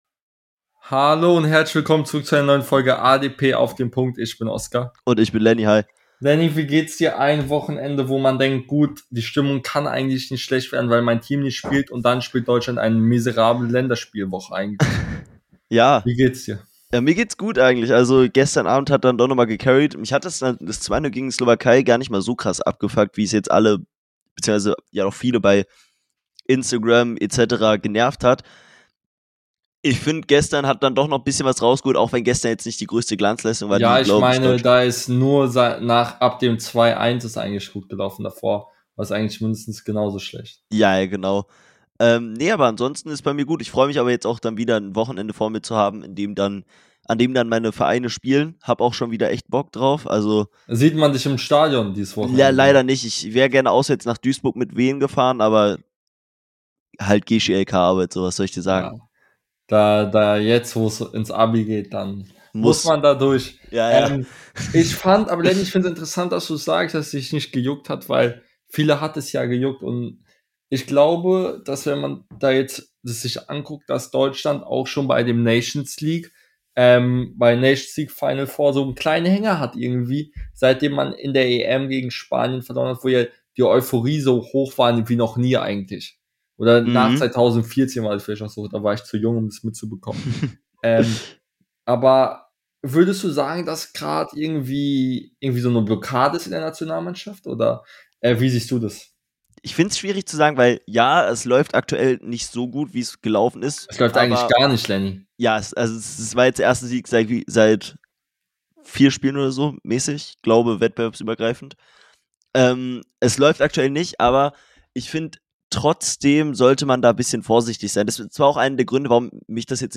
In der heutigen Folge reden die beiden Hosts über die Länderspiele , gehen mit den Spielern hart ins Gericht und blicken auf den nächsten Bundesligaspieltag